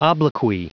Prononciation du mot obloquy en anglais (fichier audio)
Prononciation du mot : obloquy